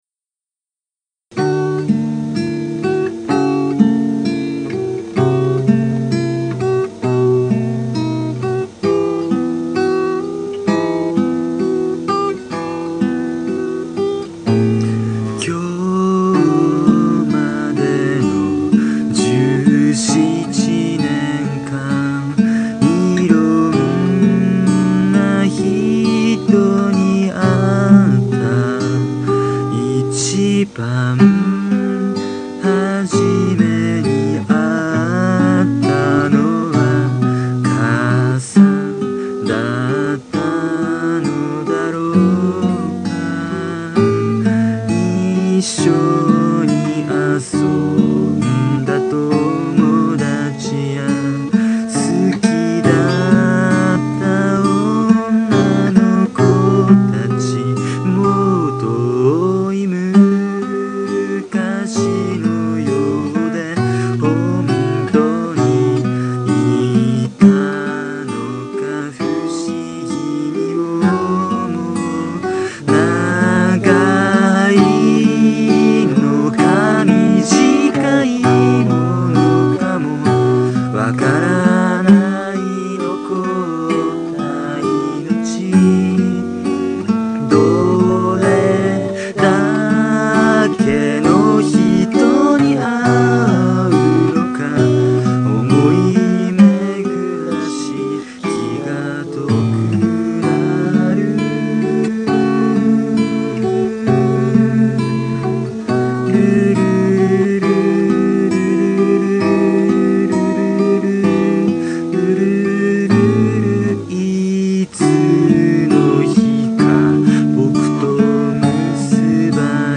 （昭和録音版）